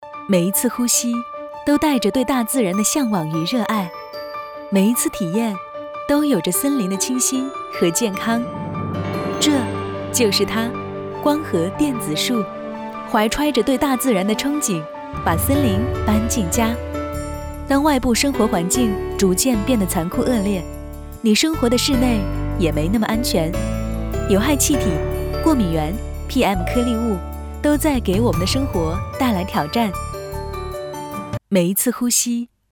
科技感女12号（电子树）
轻松自然 电子科技宣传片配音
品质女声。风格甜美、抒情、时尚。